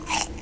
eat-bite2.wav